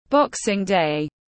Boxing day /ˈbɒk.sɪŋ ˌdeɪ/